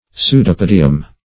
Pseudopodium \Pseu`do*po"di*um\, n.; pl. Pseudopodia. [NL.]
pseudopodium.mp3